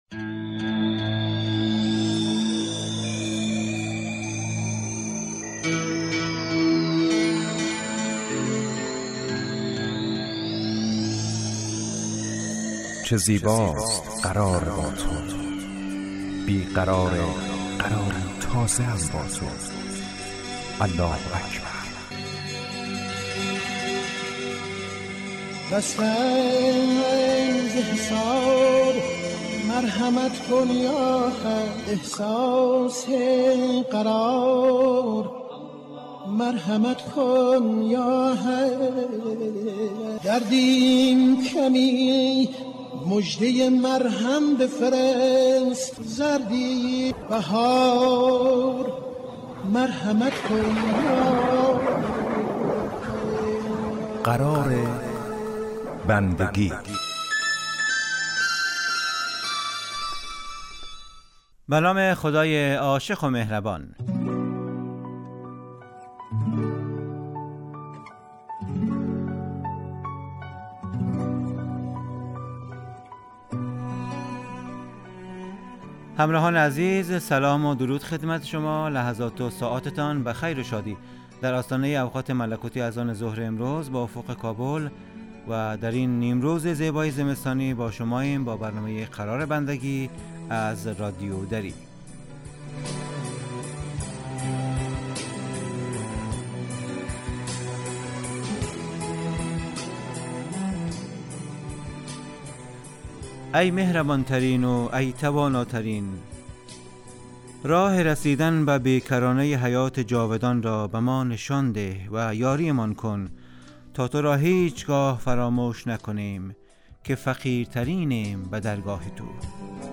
قرار بندگی برنامه اذانگاهی در 30 دقیقه هر روز ظهر پخش می شود.